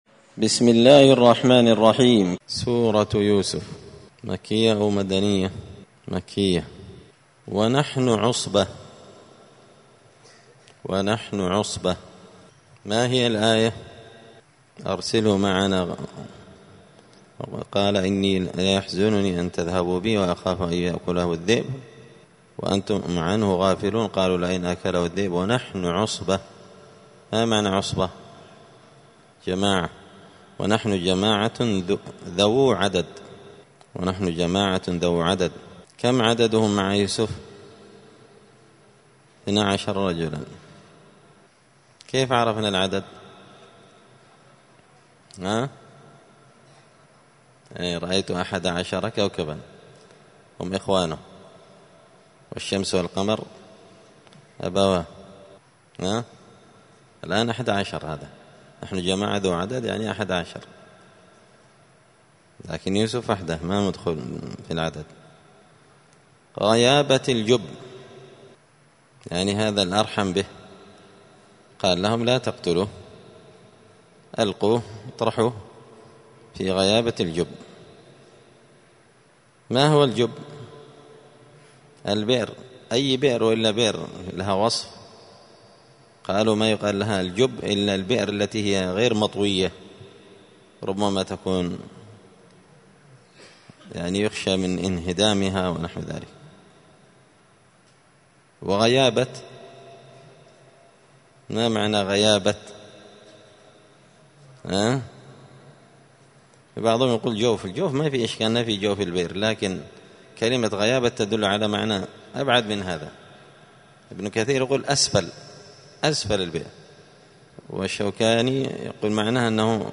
دار الحديث السلفية بمسجد الفرقان بقشن المهرة اليمن
*المجالس الرمضانية لفهم معاني السور القرآنية*